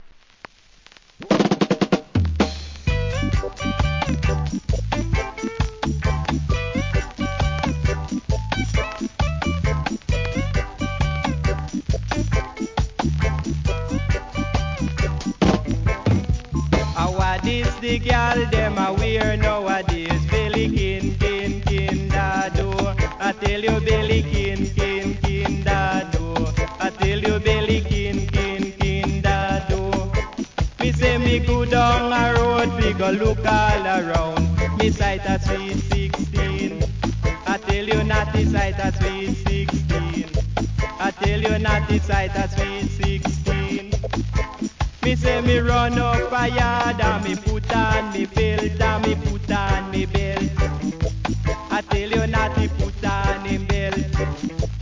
REGGAE
DeeJay STYLE!!